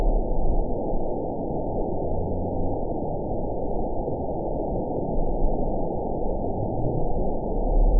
event 911205 date 02/17/22 time 13:15:22 GMT (3 years, 3 months ago) score 5.94 location TSS-AB04 detected by nrw target species NRW annotations +NRW Spectrogram: Frequency (kHz) vs. Time (s) audio not available .wav